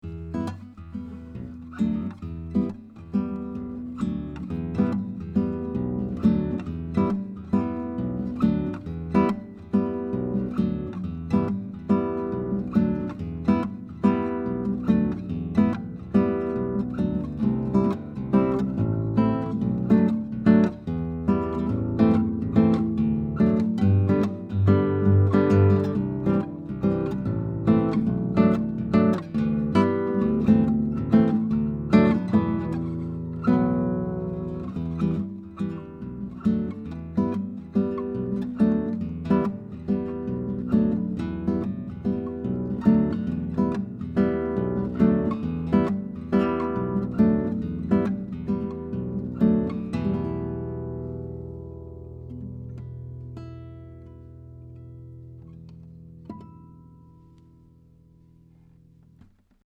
SAKURAI HARP GUITAR